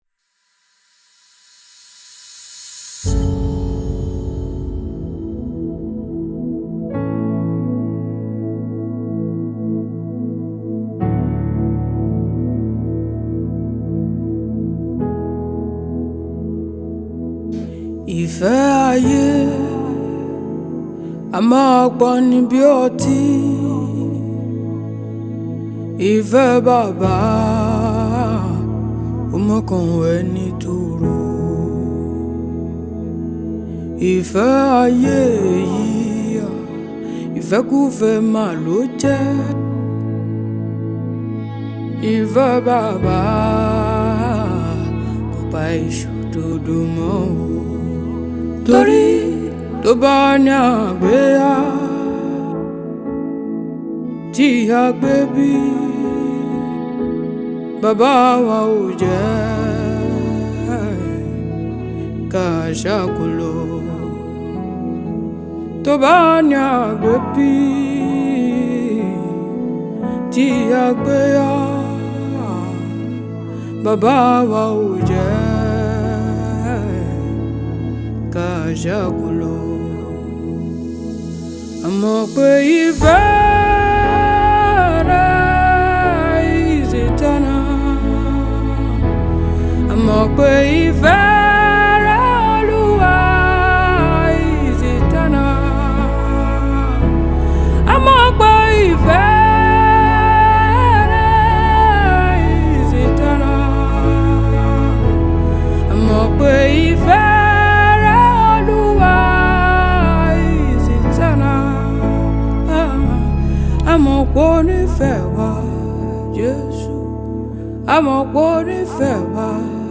Afro Fuji Pop, Gospel, Highlife
Yoruba Fuji song